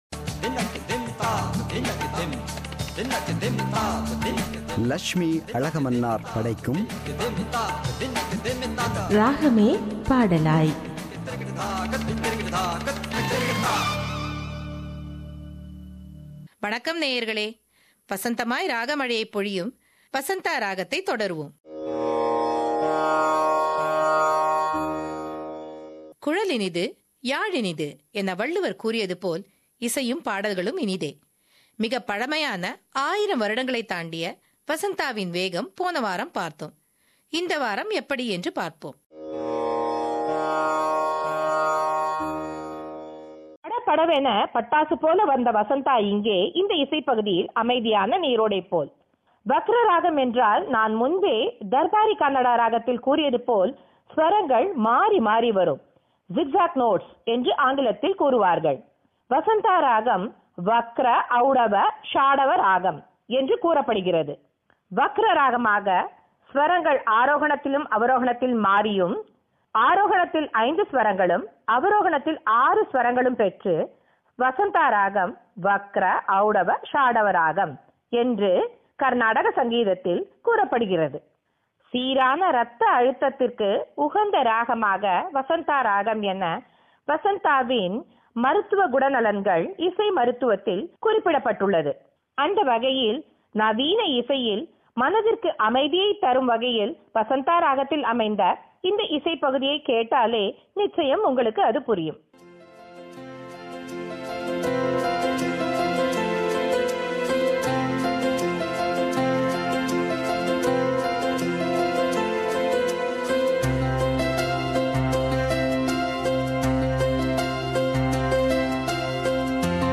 “Ragame Padalaay” – Musical Program –Part 12